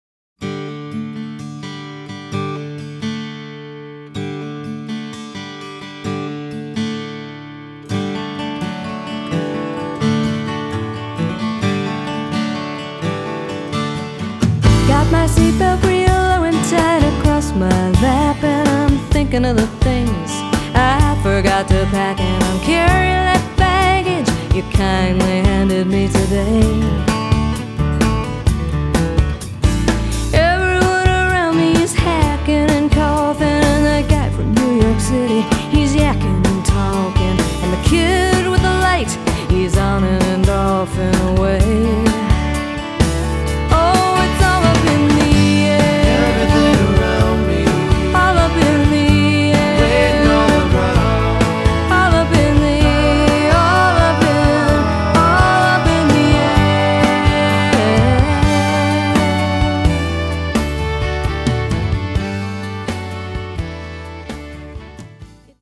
drums
bass
on mandolin and bouzouki
on electric violin.